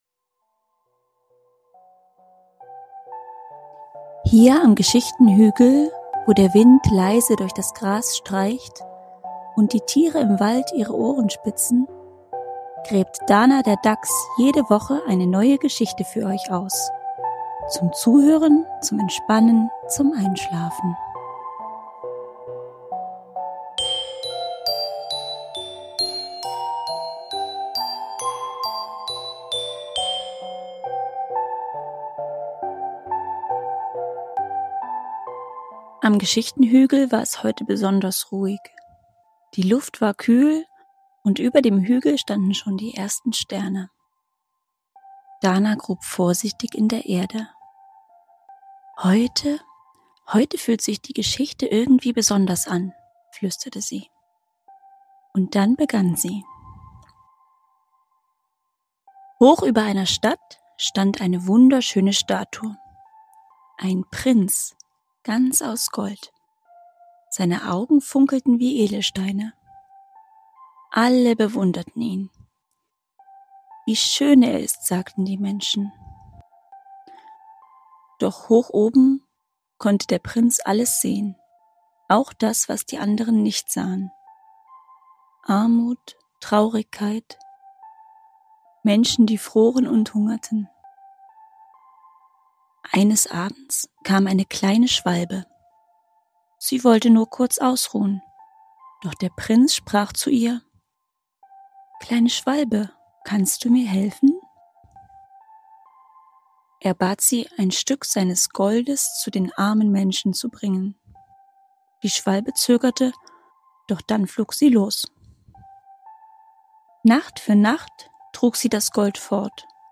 Ruhige Geschichten für Kinder – zum Entspannen, Zuhören und Einschlafen.